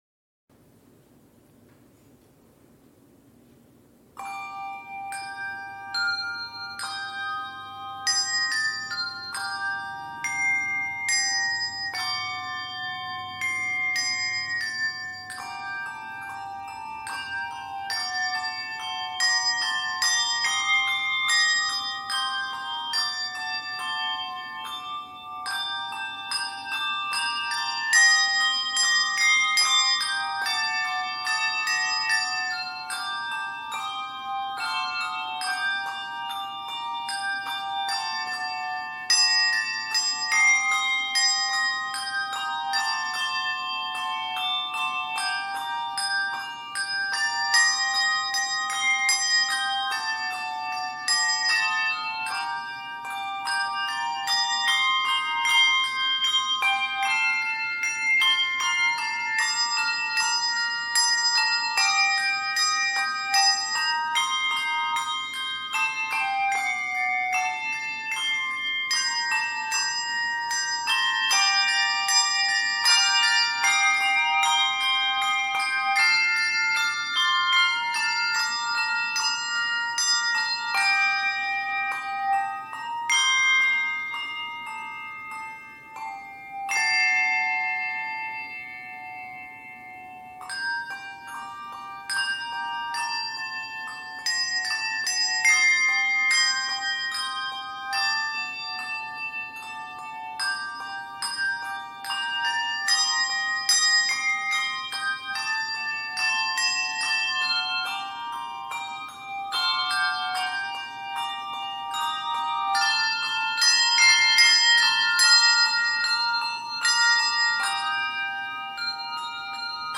Piano accompaniment is optional.